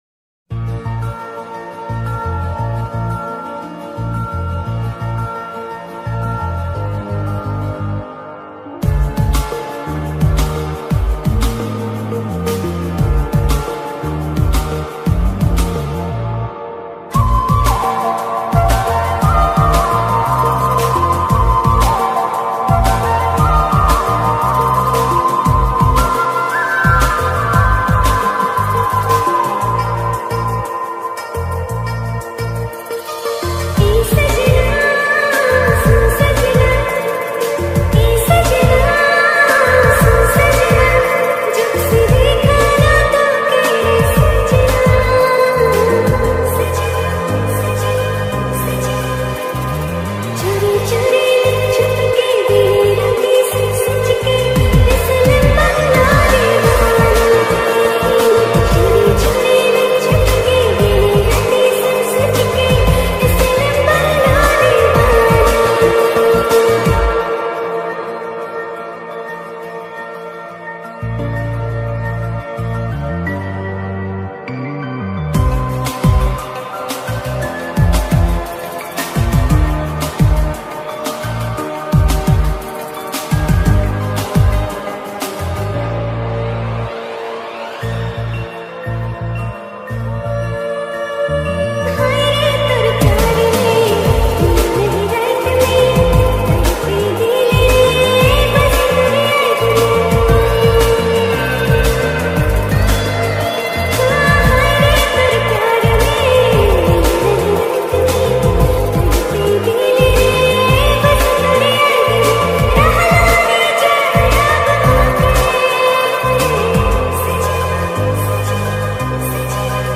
Nagpuri Remix